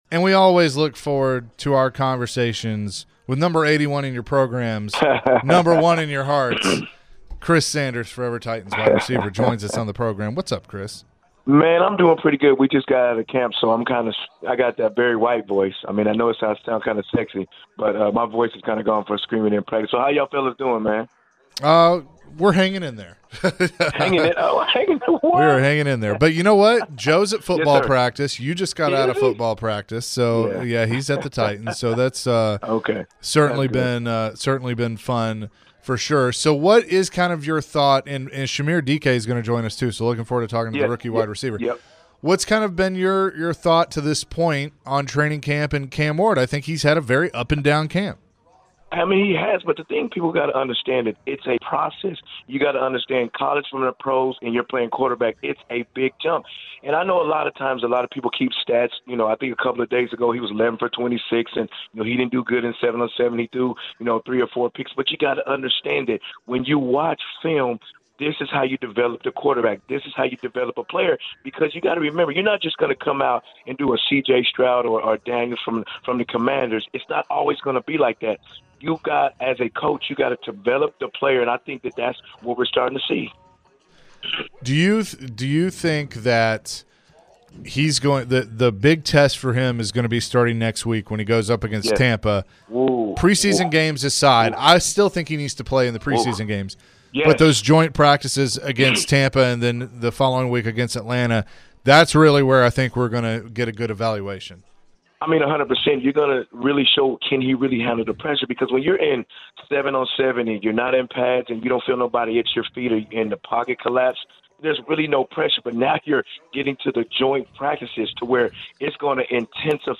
Forever Titans WR Chris Sanders joins the show, sharing his thoughts on Cam Ward and the Titans training camp as a whole. Chris also shared his thoughts on some other player standouts from practice.